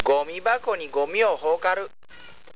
方言